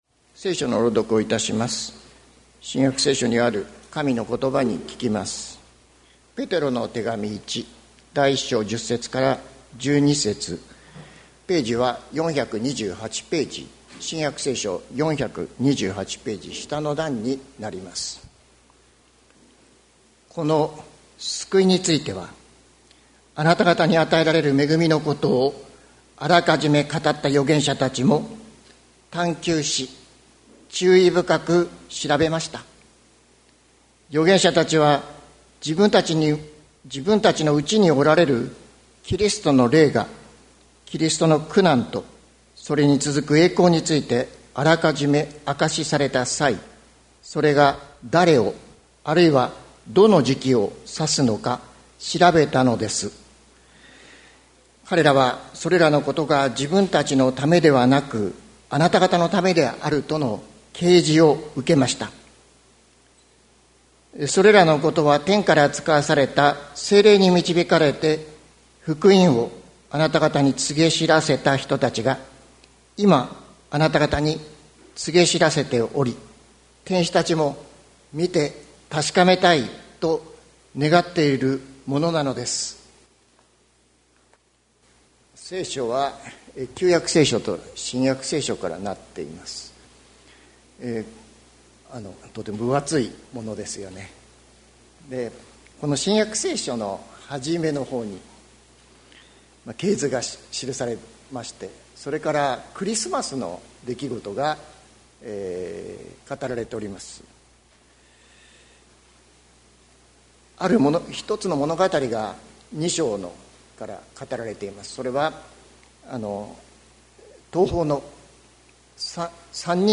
2024年08月18日朝の礼拝「あなたがたのための救い」関キリスト教会
説教アーカイブ。